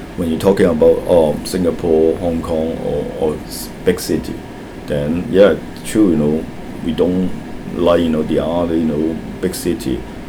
S1 = Taiwanese female S2 = Hong Kong male Context: S2 is talking about extensive usage of cars in the United States and also in Brunei, unlike in other big cities.
The problem is the omitted medial voiced TH.